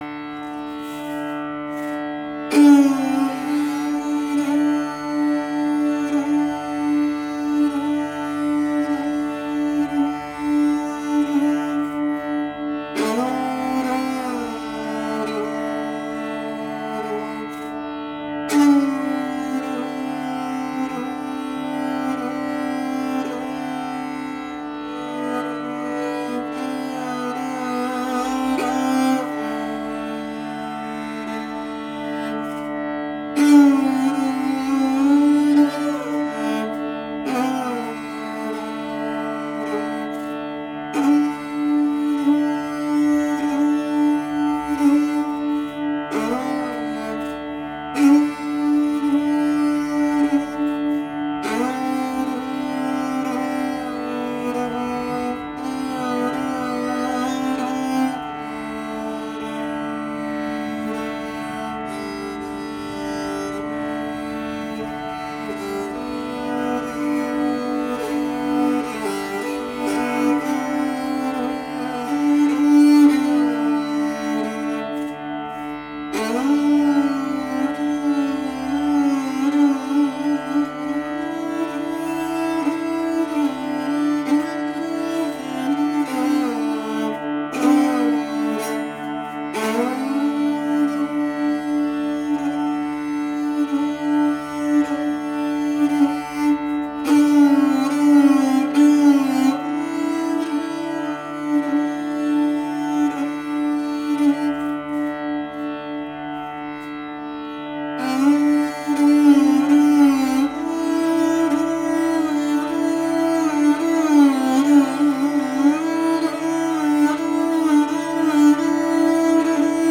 I learned classical esraj (a version of sitar played with a bow) from three eminent musicians, one of them from Santiniketan, and the others from Mumbai.